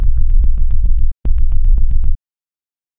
Casino Sound Effects - Free AI Generator & Downloads